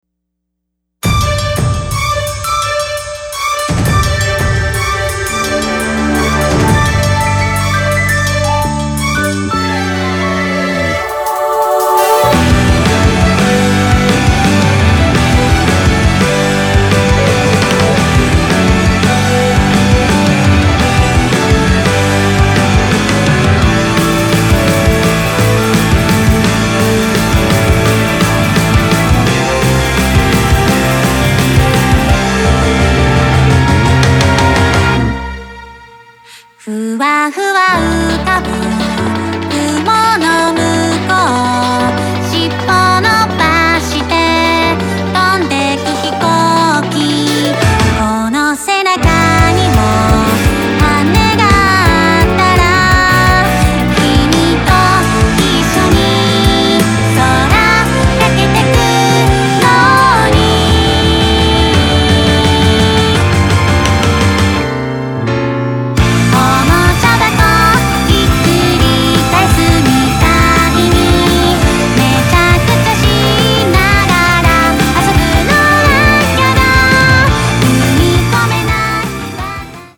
儚さポップ＆ロック全開！
Guitar
Bass
Piano & strings